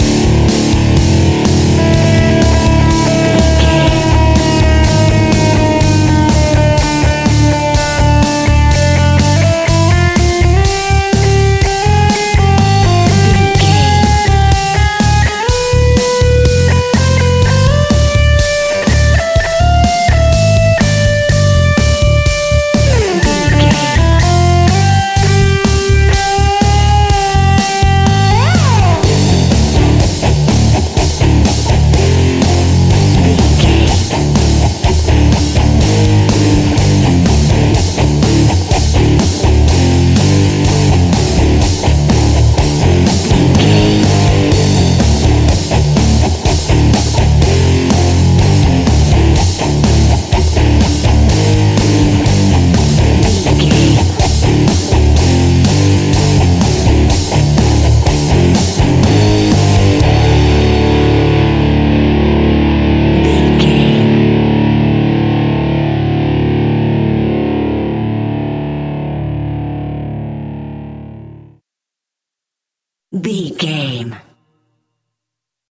Ionian/Major
energetic
driving
heavy
aggressive
electric guitar
bass guitar
drums
electric organ
hard rock
heavy metal
distortion
distorted guitars
hammond organ